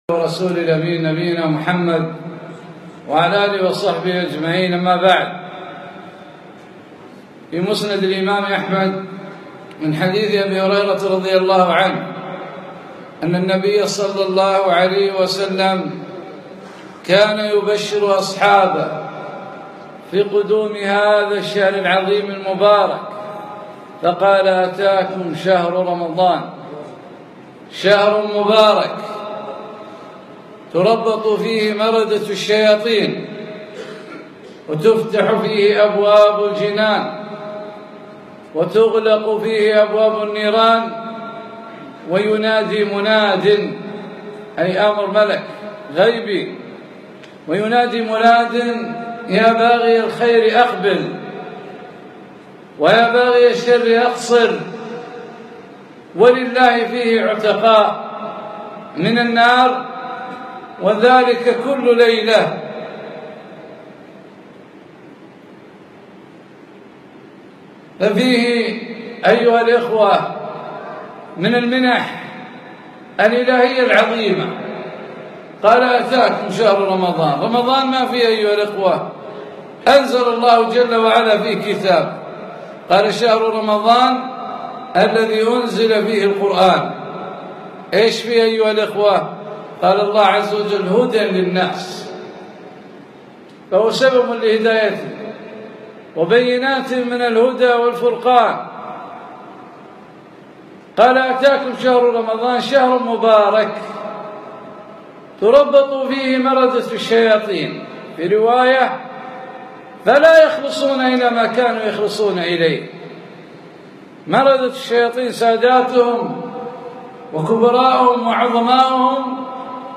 يوم الخميس 11 رمضان 1437 في مسجد سالم العلي الفحيحيل